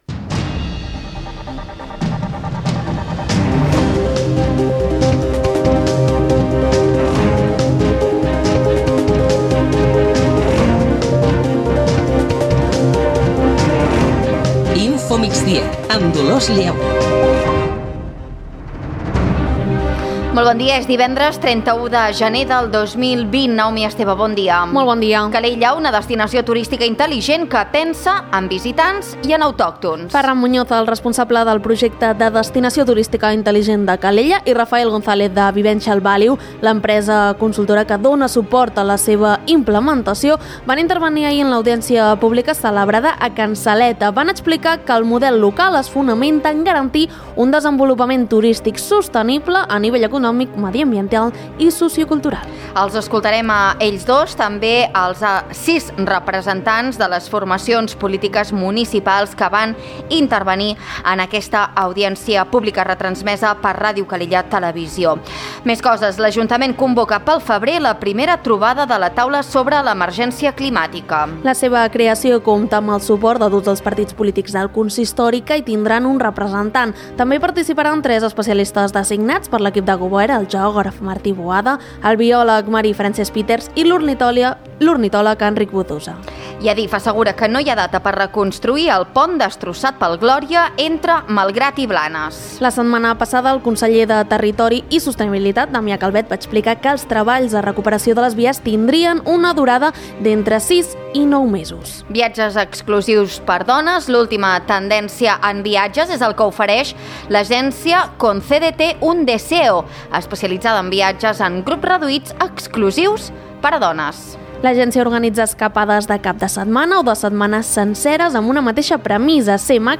Notícies d'actualitat local i comarcal.